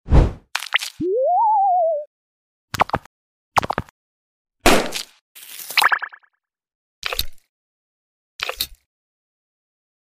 Cute FACE Soothing ASMR Squishy